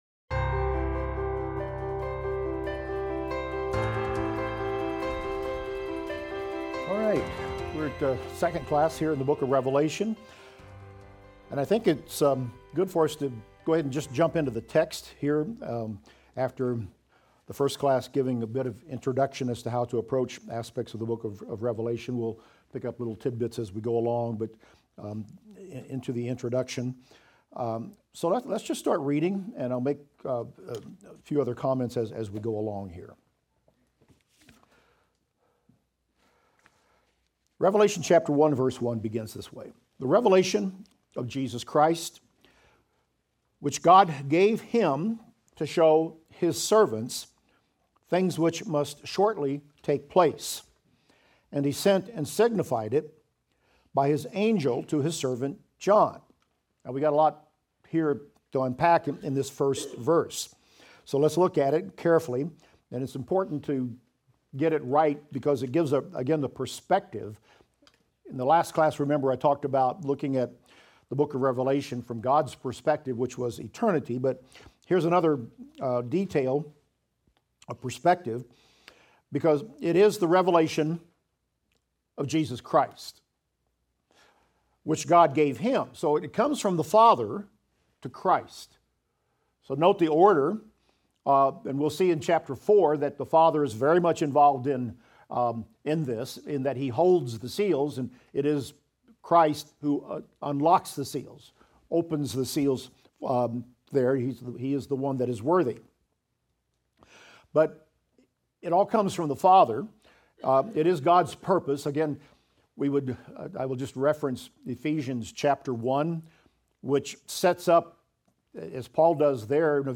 Revelation - Lecture 26 - Audio.mp3